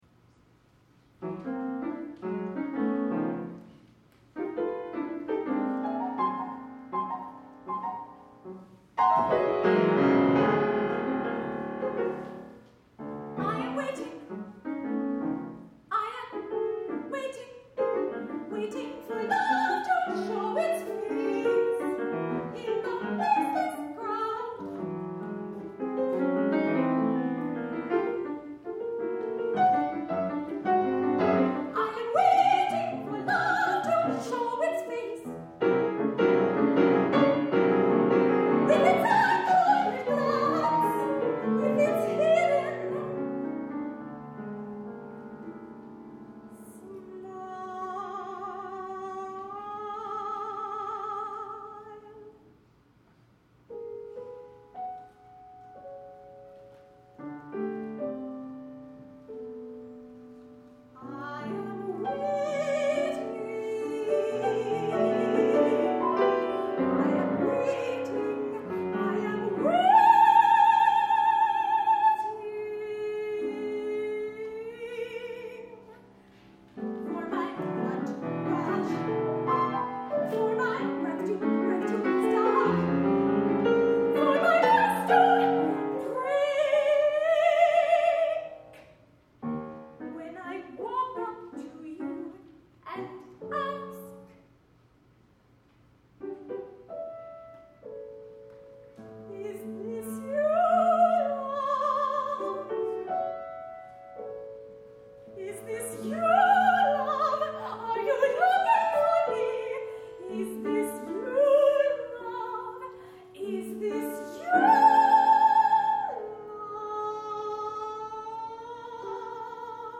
soprano and piano